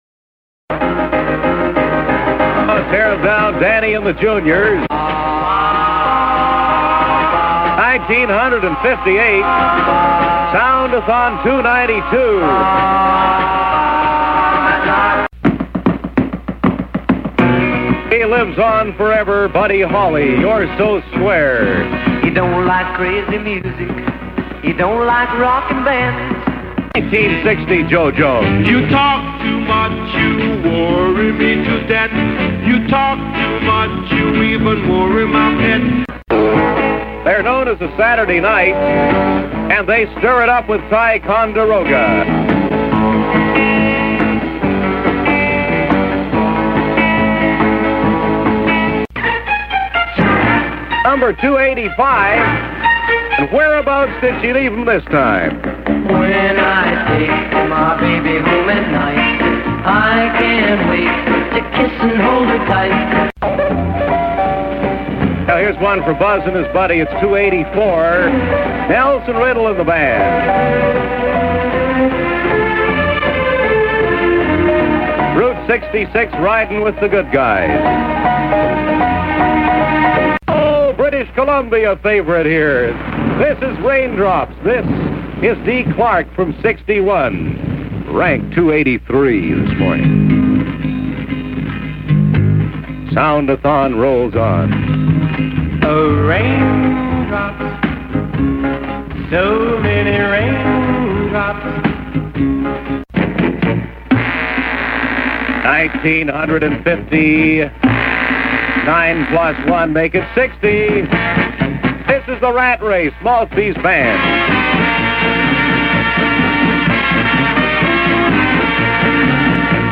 The C-FUN Good Guys introduce the songs in these audio clips recorded directly from the radio during the actual event in December 1962
soundathon05montage.mp3